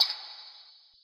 perc 15.wav